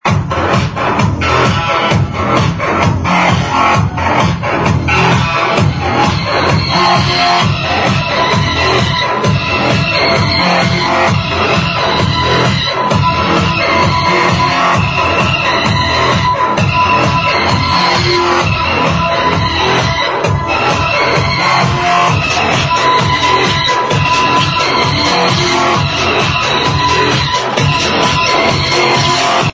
Question Amazing electro-tech house sample to id